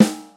• 2000s Short Acoustic Snare Sample G# Key 47.wav
Royality free steel snare drum sample tuned to the G# note.